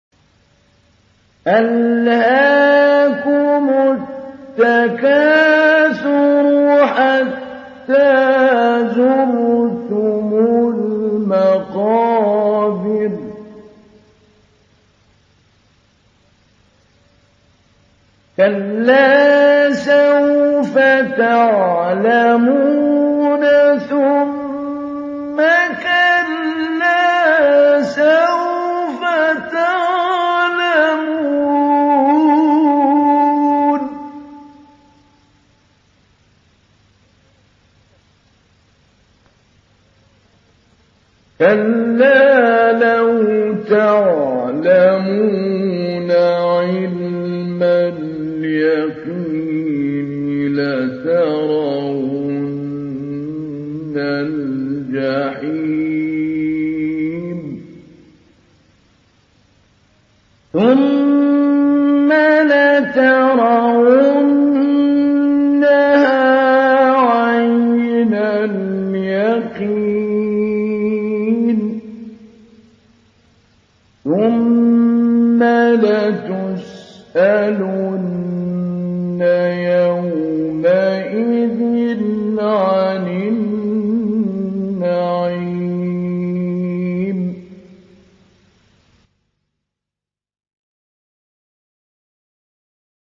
102. Surah At-Tak�thur سورة التكاثر Audio Quran Tajweed Recitation
Surah Repeating تكرار السورة Download Surah حمّل السورة Reciting Mujawwadah Audio for 102.